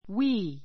we 小 A1 wi(ː) ウィ(ー) 代名詞 ❶ 私たちは , 私たちが , 我々は[が] ⦣ I 2 の複数形.